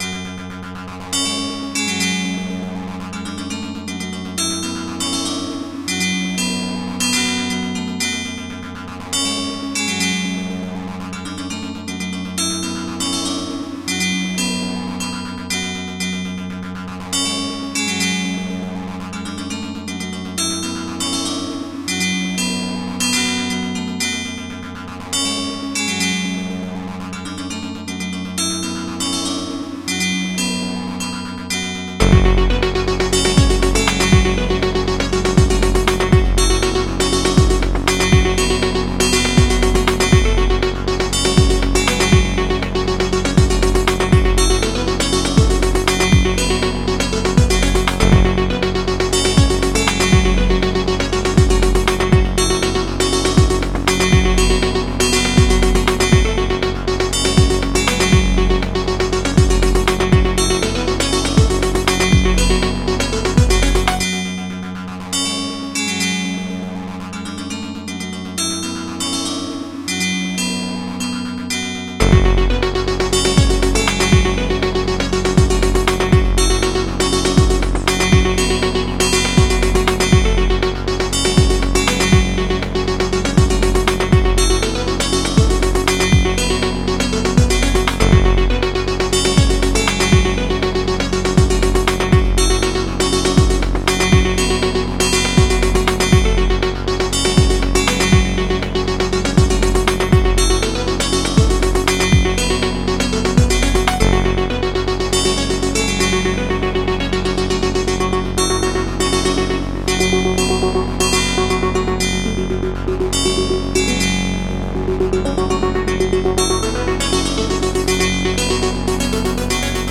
Bass heavy and long (6+ min) energetic tune for sports and/or race kinda games - and the sorts.
You be the judge :) But it does have heavy hitting bass, so you know.. Mind your speakers :) Designed to be loopable so cut any "beat cycle" and loop it. 120BPMBut just in case it's easier for you to just loop it from begin to end, I made this long version.